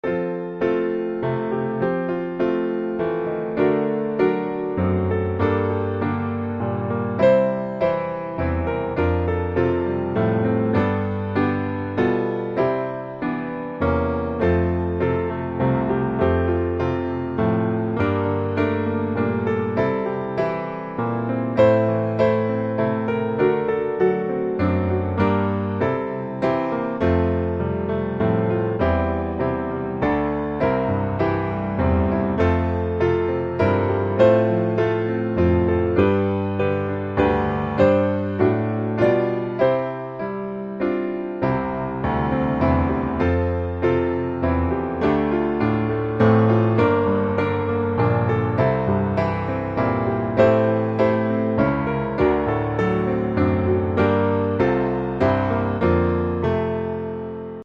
F大調